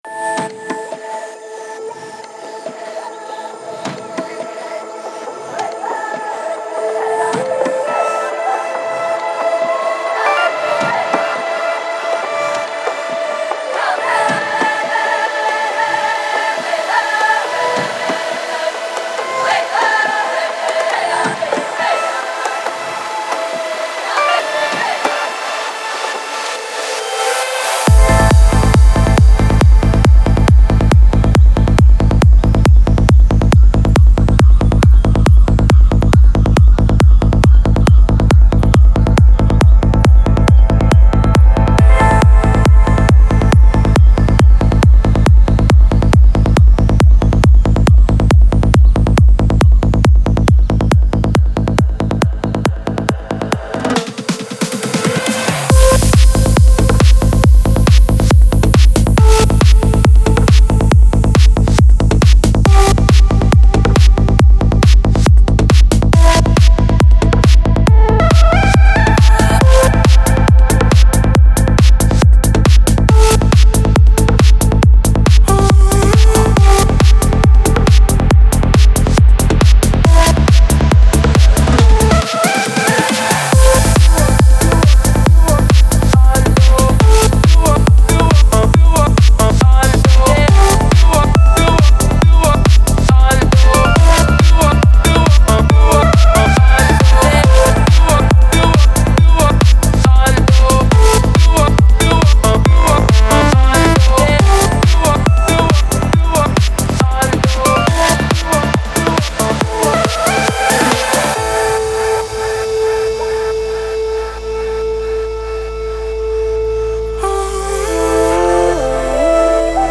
پر‌انرژی , ترنس , موسیقی بی کلام , ورزشی
موسیقی بی کلام پر انرژی